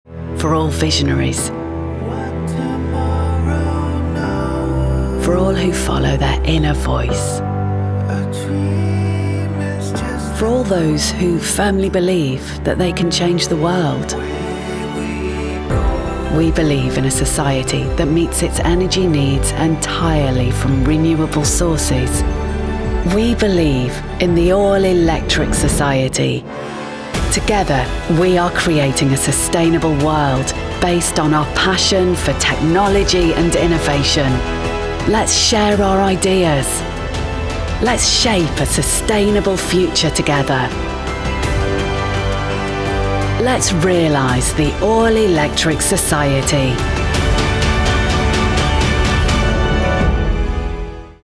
Inglés (Británico)
Llamativo, Versátil, Amable
Corporativo